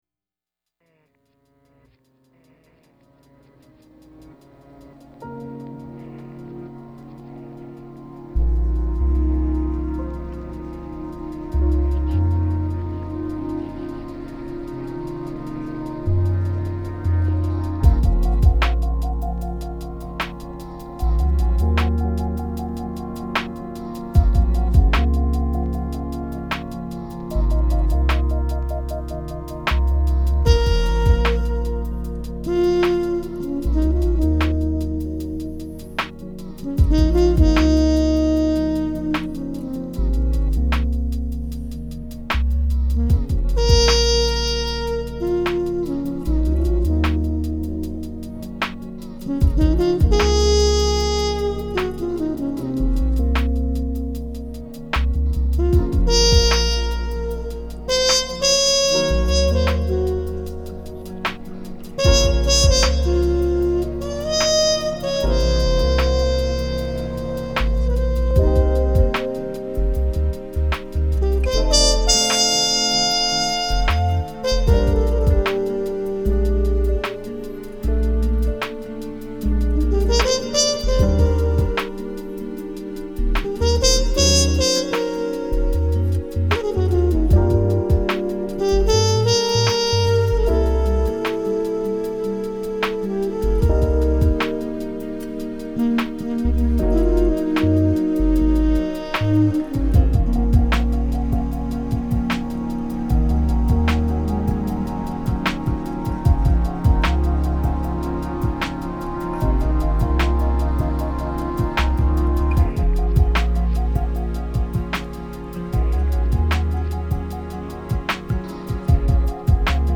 Твой джаз ночных проспектов...